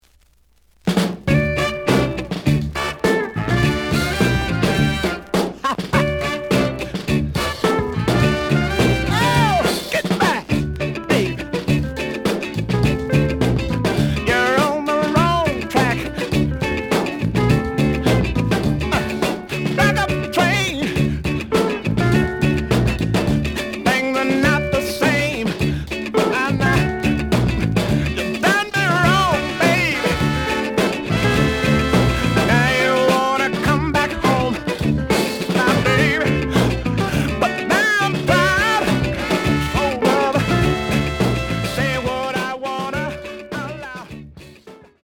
The audio sample is recorded from the actual item.
●Genre: Soul, 70's Soul
Looks good, but slight noise on both sides.)